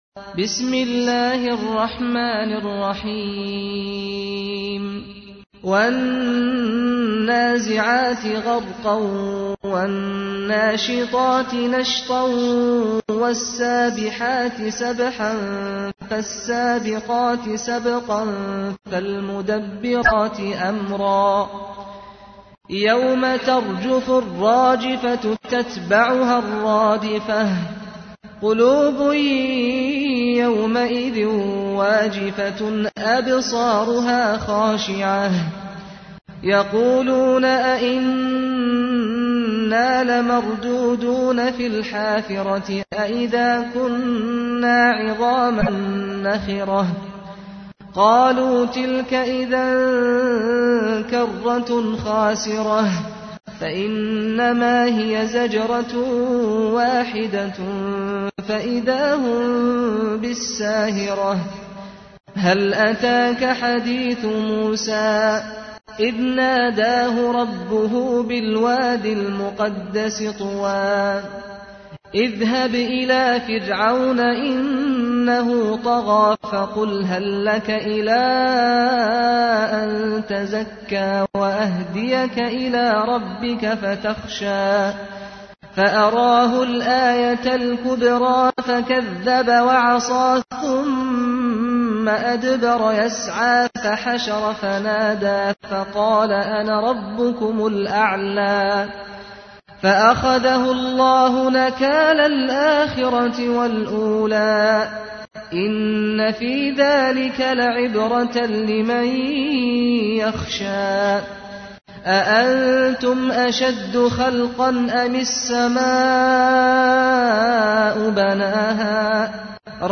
تحميل : 79. سورة النازعات / القارئ سعد الغامدي / القرآن الكريم / موقع يا حسين